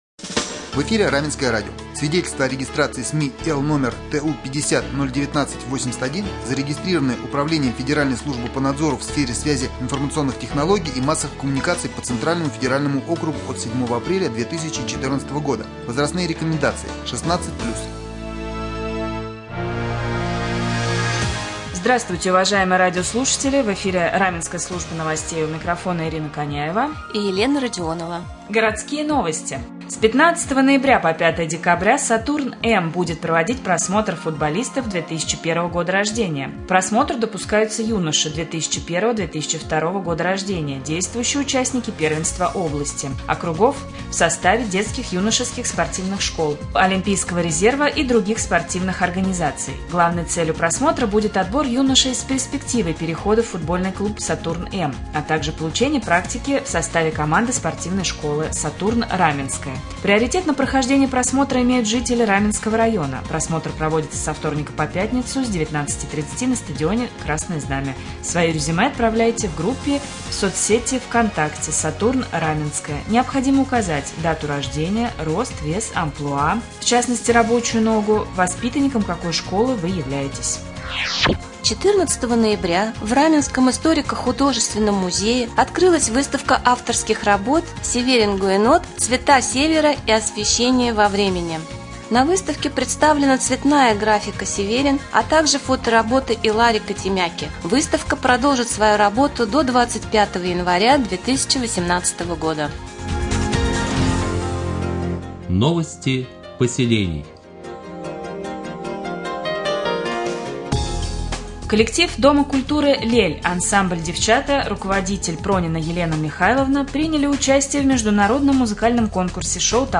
Сегодня в новостном выпуске на Раменском радио Вы узнаете, как попасть в команду «Сатурн-М», какая выставка открылась в Раменском музее, а также последние областные новости и новости соседних районов.